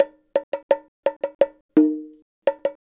Bongo 09.wav